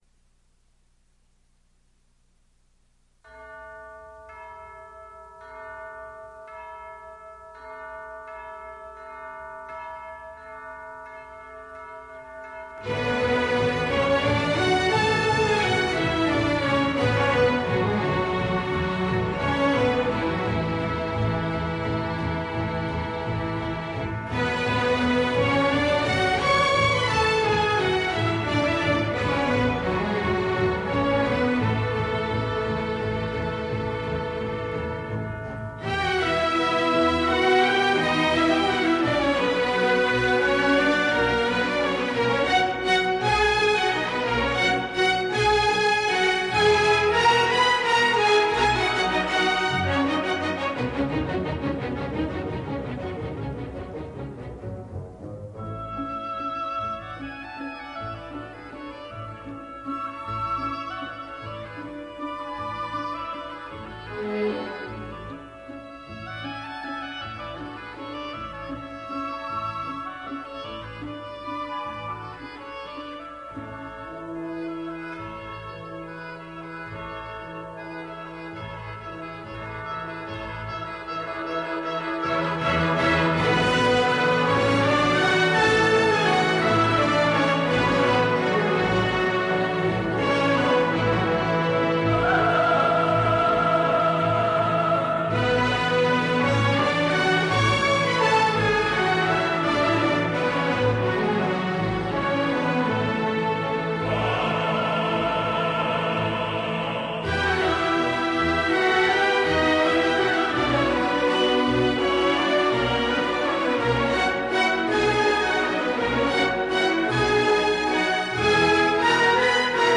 Coro,